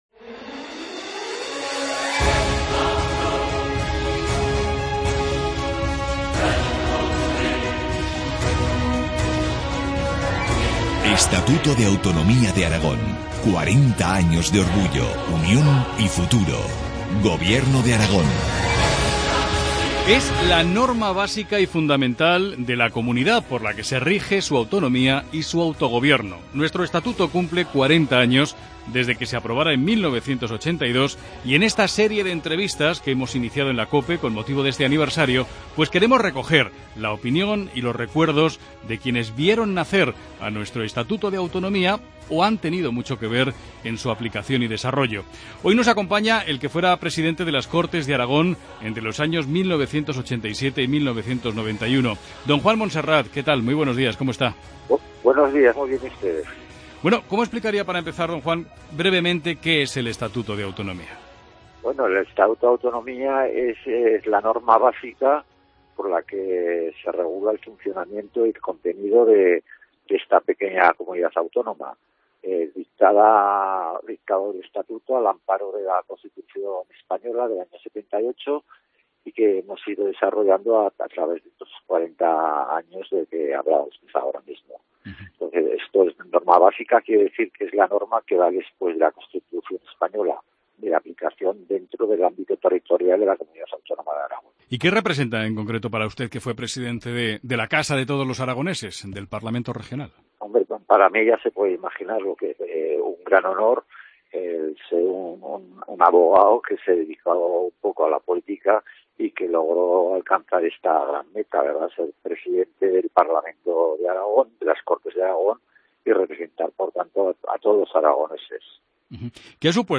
Entrevista al ex presidente de las Cortes de Aragón entre 1987 y 1991, Juan Monserrat.